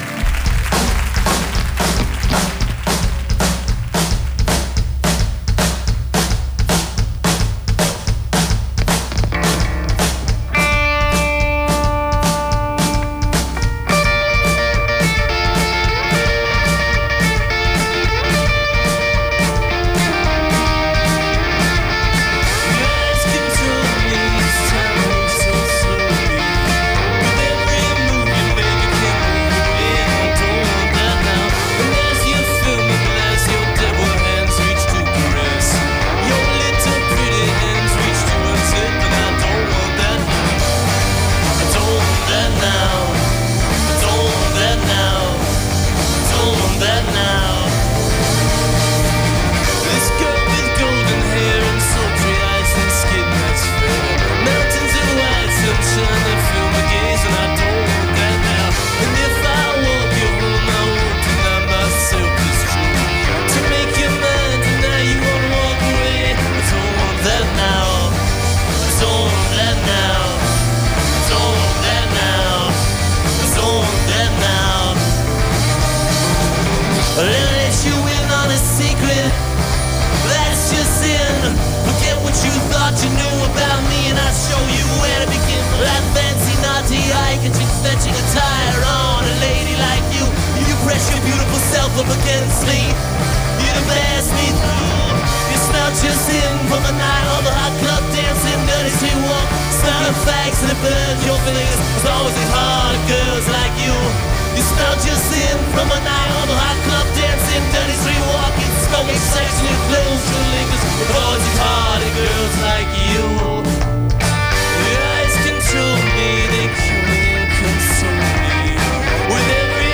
enregistrée le 17/03/2008  au Studio 105